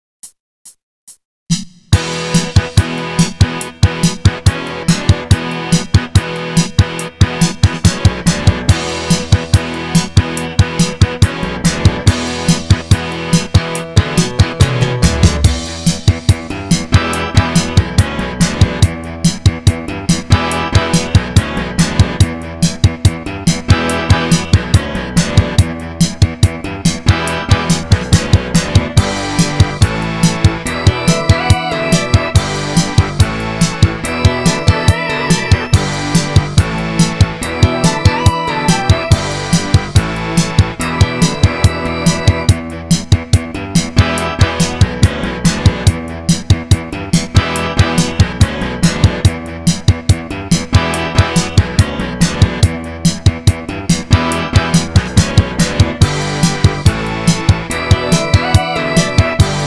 固件firm230328中，增加了新的“舞曲”功能，固件中给内置了30+首劲爆舞曲。
舞曲片段2